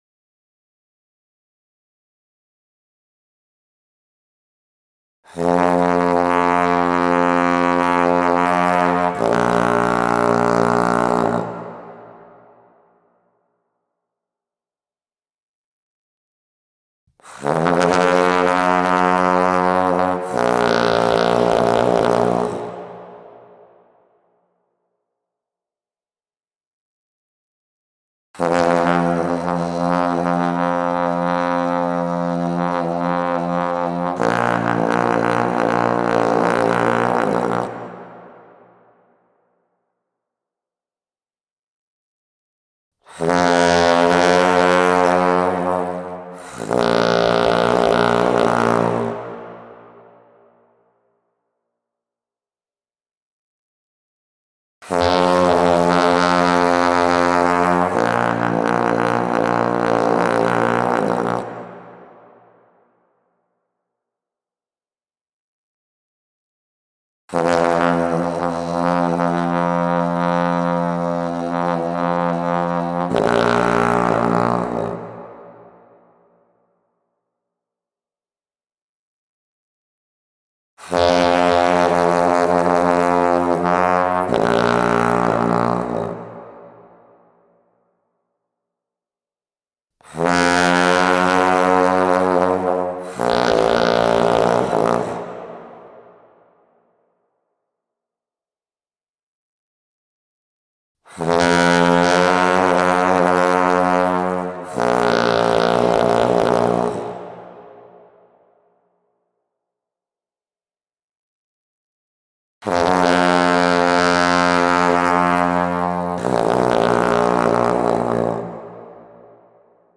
Bass trombone